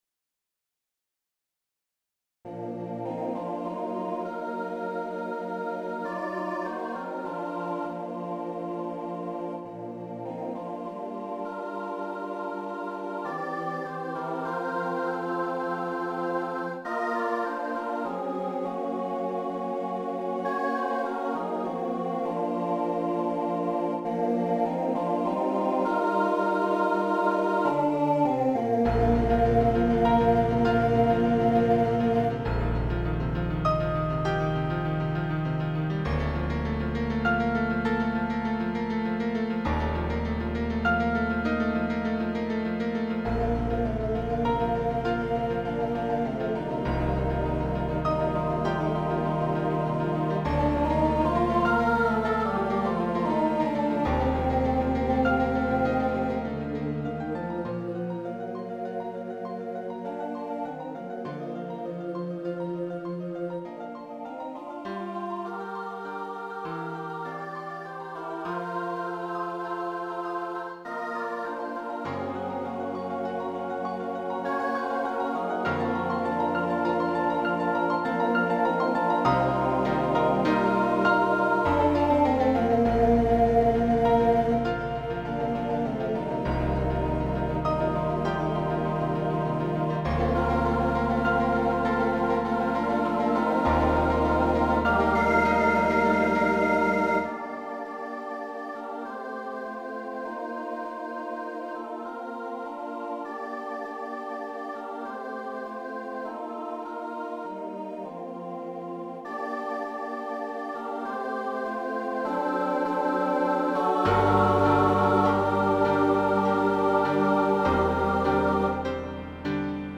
SATB choir and piano
demo